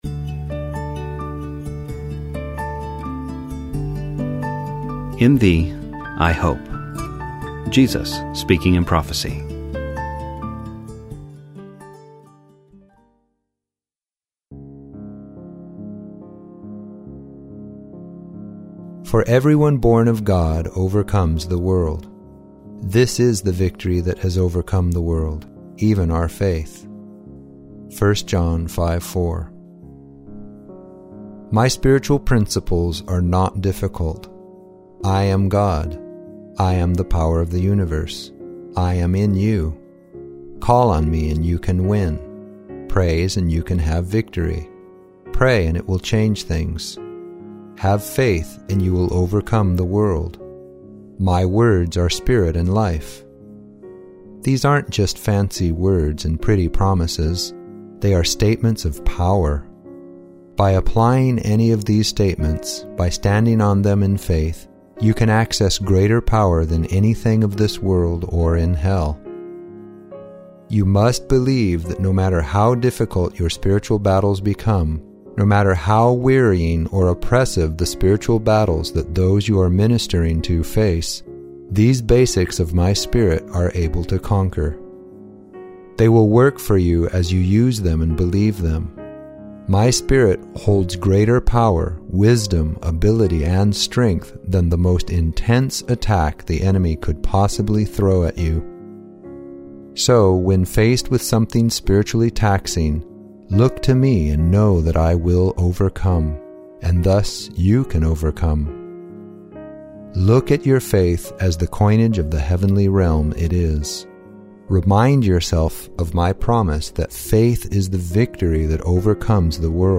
TFI_Devotional_In_Thee_I_Hope.mp3